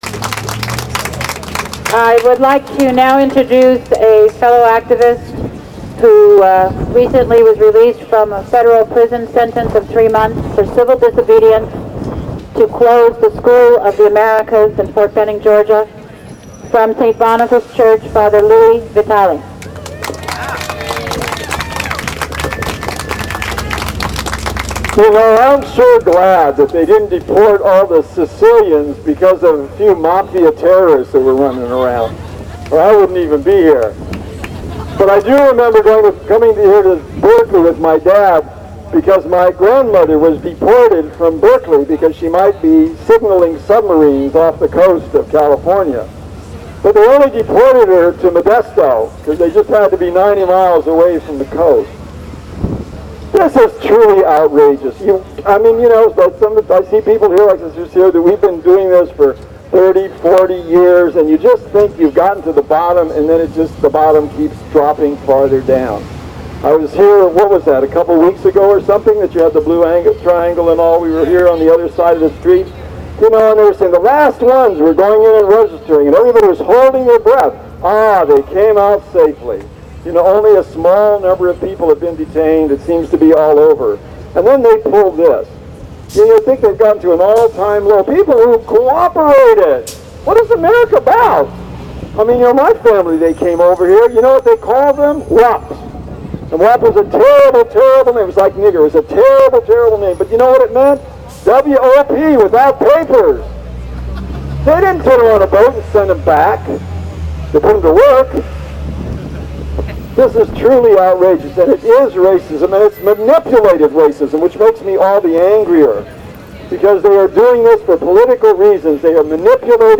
This footage is from the protest in front of the INS building that took place from noon to 1pm at 444 Washington Street in San Francisco on June 13, 2003.